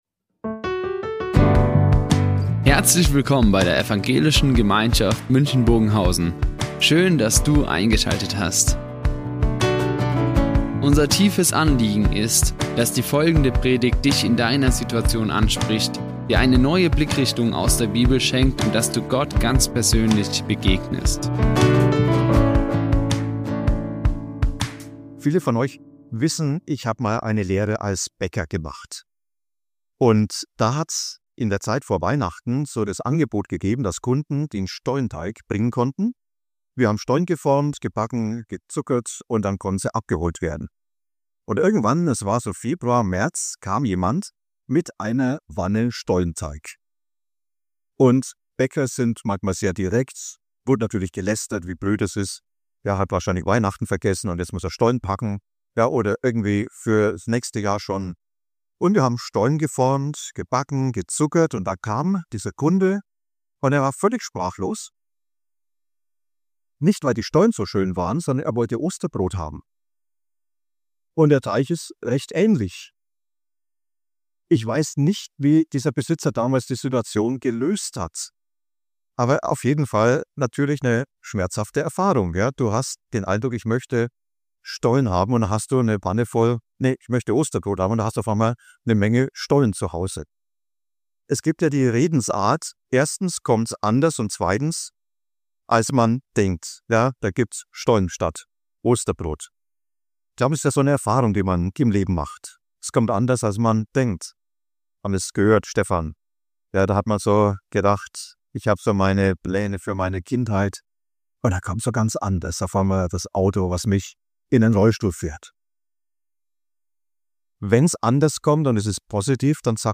Meine Geschichte SEINE Geschichte ~ Ev. Gemeinschaft München Predigten Podcast